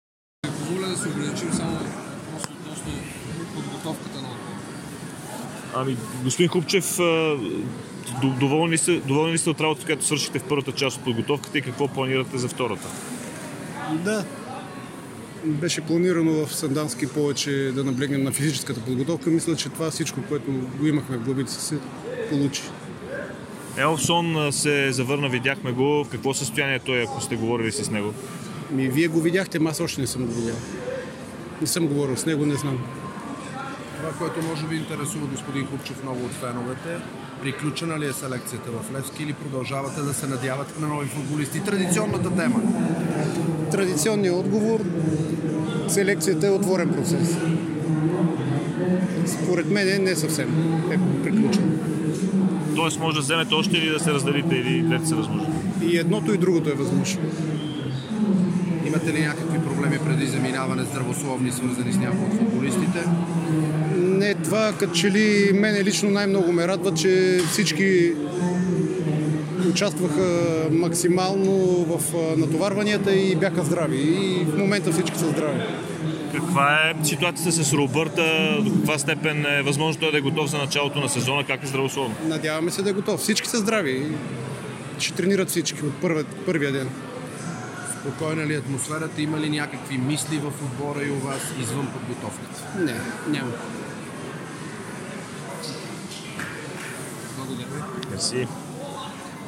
Всички започват тренировки още от първия ден в Турция", каза той на летище "София"